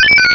Cri d'Axoloto dans Pokémon Rubis et Saphir.